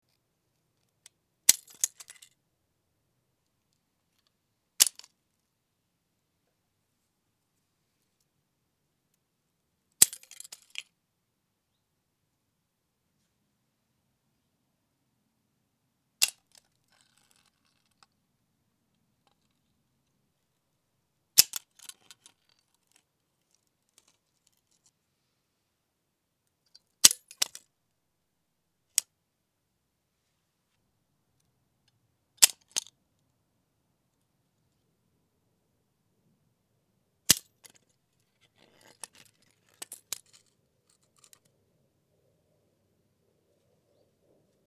Скачать звук мышеловки бесплатно
Звук захлопнувшейся мышеловки здесь можно слушать онлайн и скачать файлы в mp3 формате бесплатно.
В него вошли топ 3 самых лучших звука со срабатыванием мышеловки, которые вы можете использовать для монтажа видео.
zvuk-myshelovki-3w.mp3